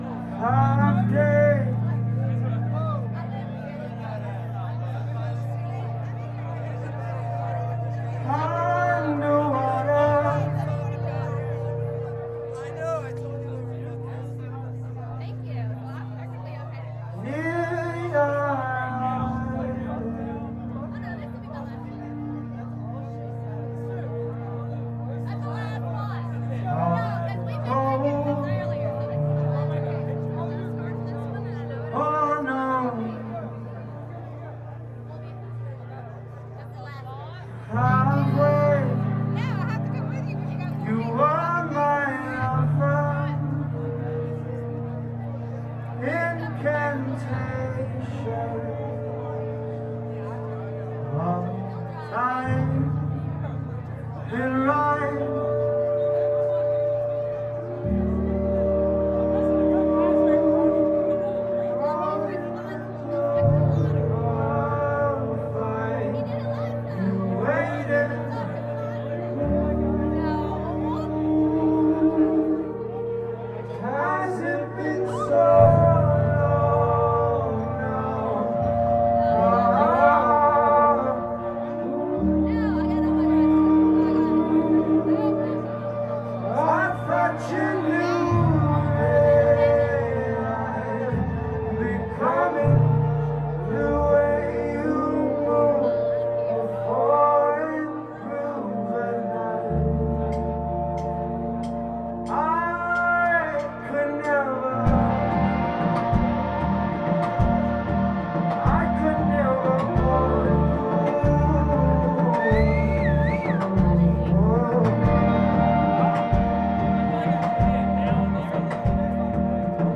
Chelsea’s Cafe
Baton Rouge, LA
the clever use of vocal trickery